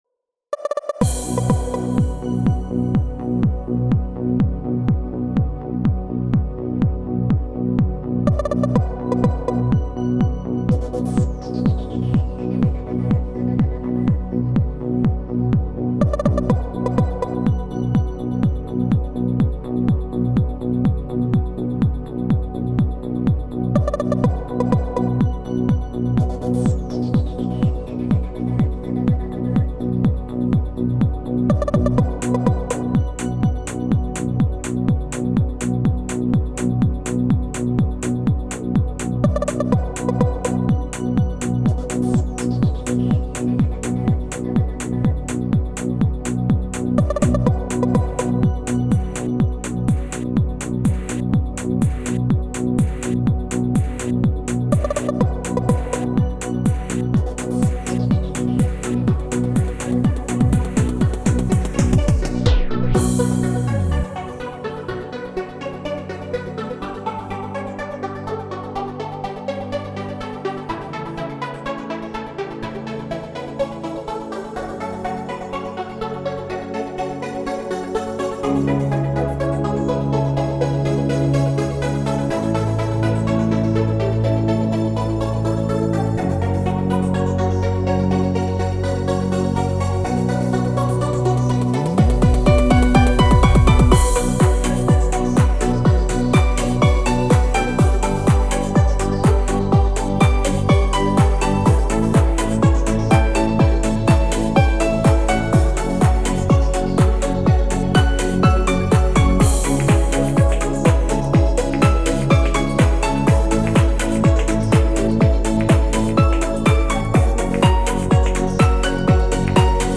Стиль: dream , Trance